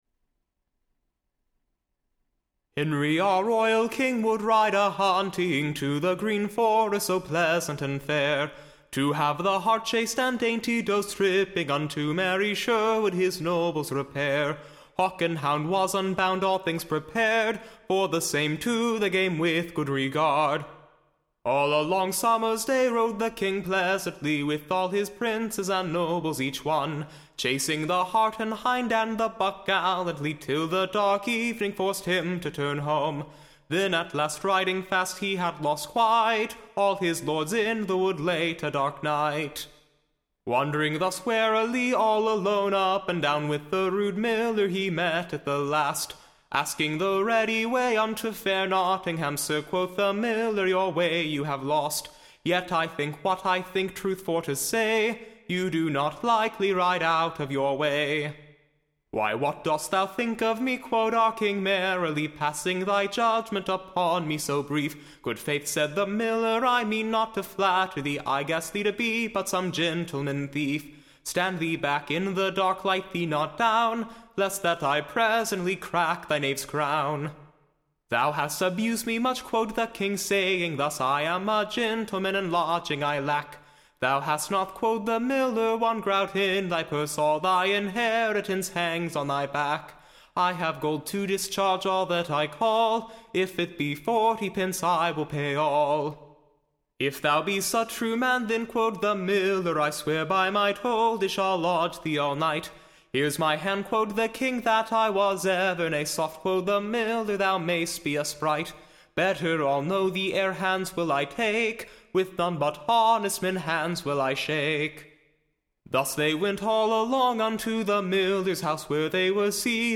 Recording Information Ballad Title [A Pleasant] BALLAD of King HENRY the Second, and the Miller of / Mansfield, and how he was Entertained and Lodged at the Miller's House, and of their / pleasant Communication.